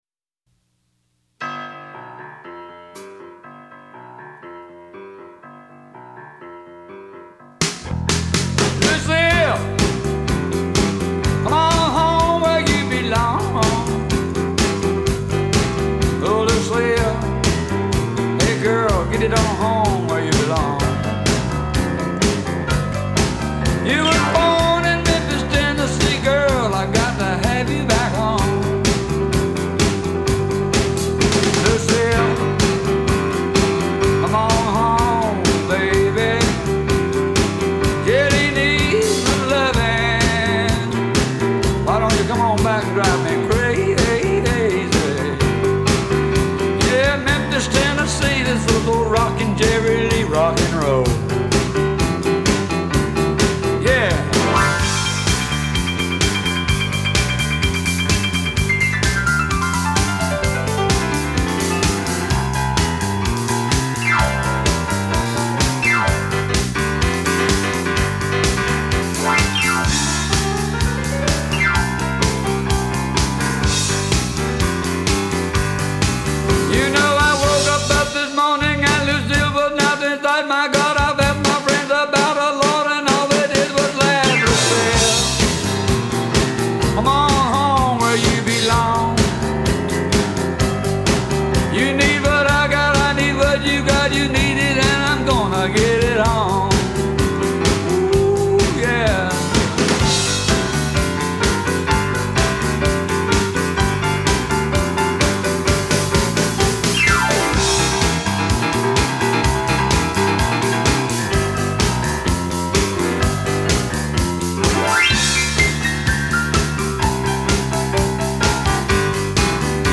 bass
drummer
in one take, straight off the studio floor